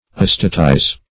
Search Result for " astatize" : The Collaborative International Dictionary of English v.0.48: Astatize \As"ta*tize\, v. i. [imp.